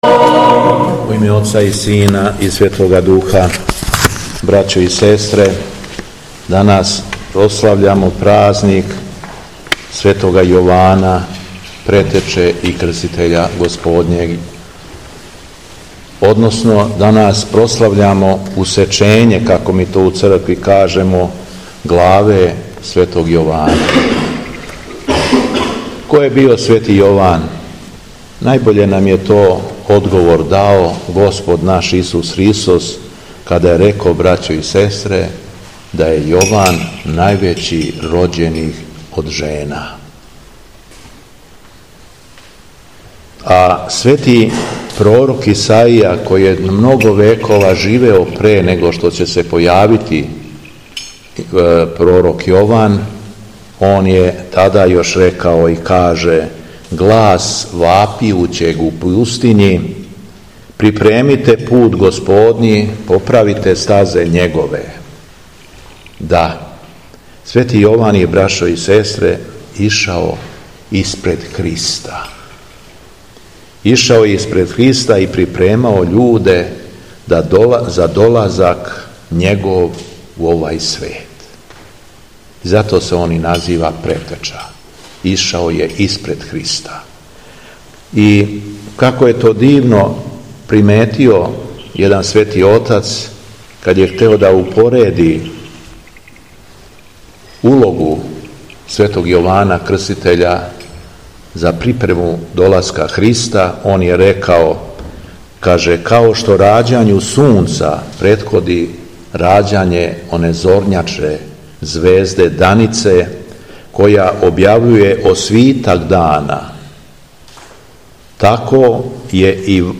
У среду, 11. септембра 2024. године, када се наша Света Црква молитвено сећа и празнује усековање главе Светог Јована Крститеља и Светог Григорија Епископа рашког, Његово Високопреосвештенство Митрполит шумадијски Господин Јован служио је Свету Архијерејску Литургију у манастиру Каленић.
Беседа Његовог Високопреосвештенства Митрополита шумадијског г. Јована